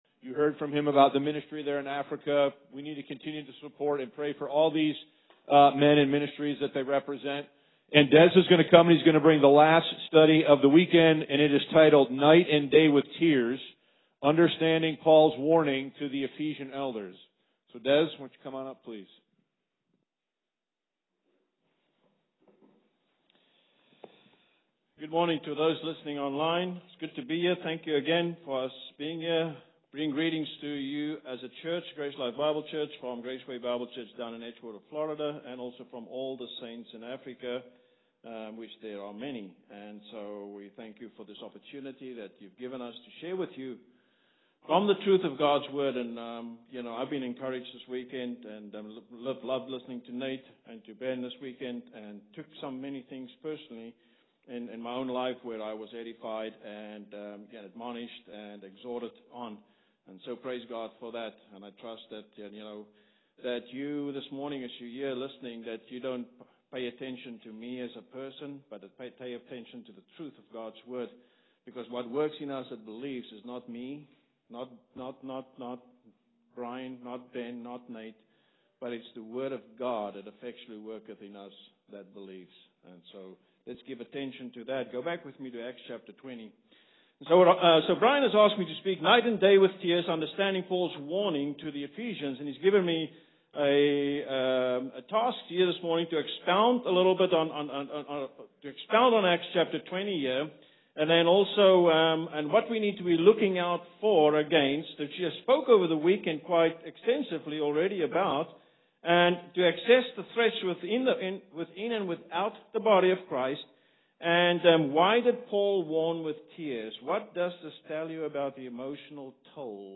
2025 West Michigan Grace Bible Conference